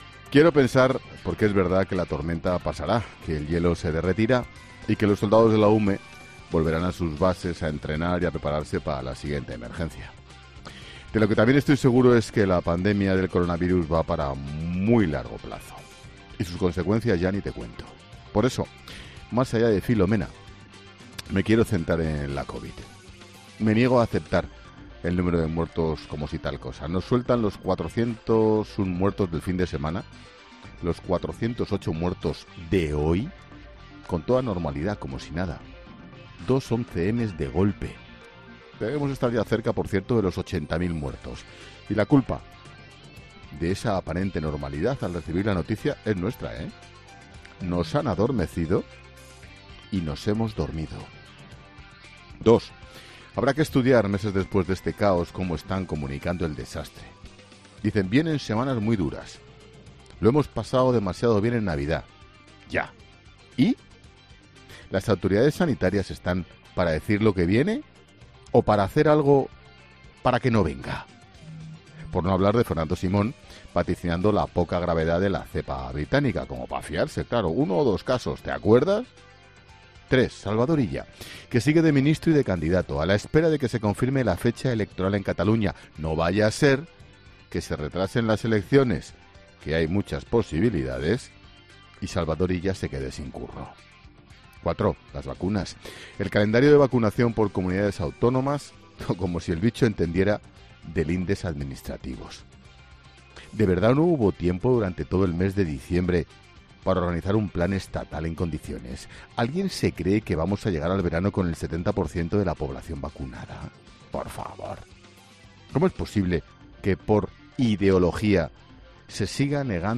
El director de 'La Linterna', Ángel Expósito, analiza hoy en su monólogo la evolución epidemiológica de la covid-19